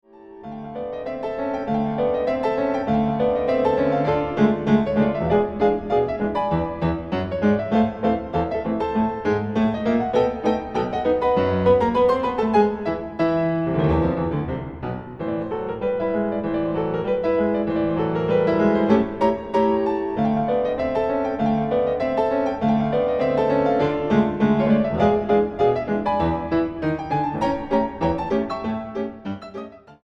Rag
piano music